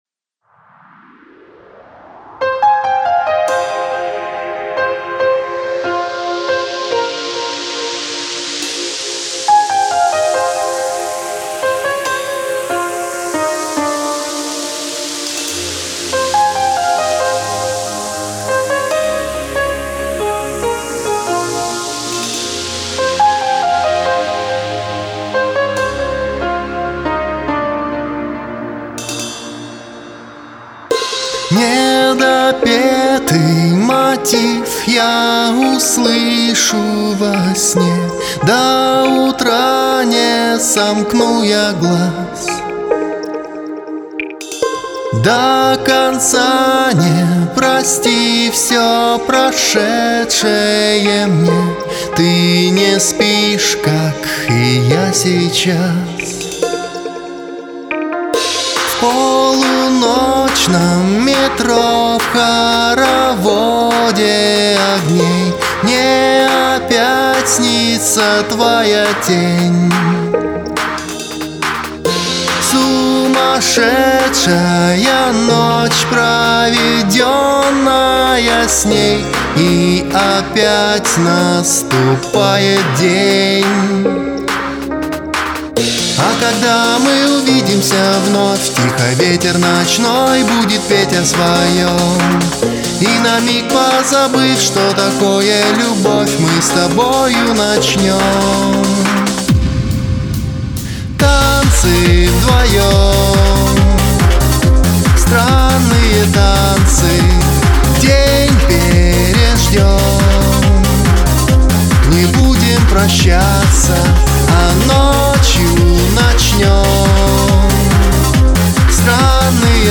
но звучание живое и слушается интересней.